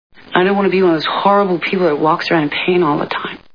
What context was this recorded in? Six Feet Under TV Show Sound Bites